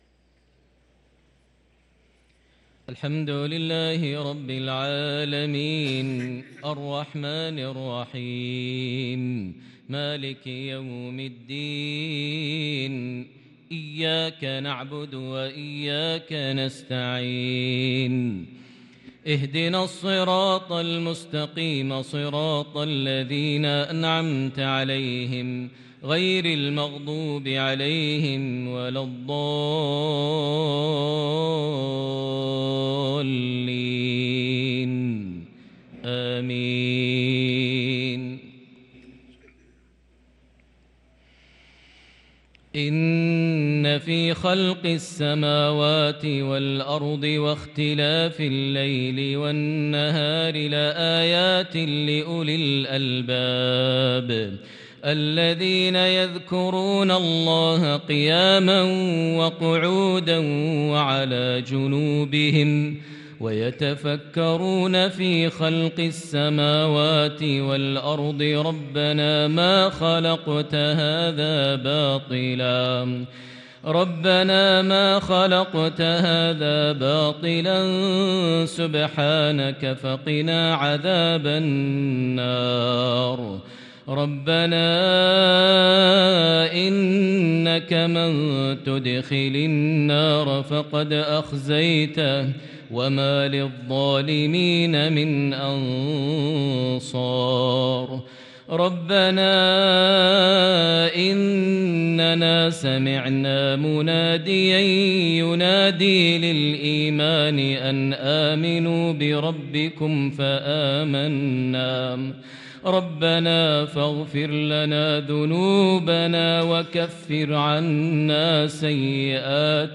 صلاة العشاء للقارئ ماهر المعيقلي 25 ربيع الأول 1444 هـ
تِلَاوَات الْحَرَمَيْن .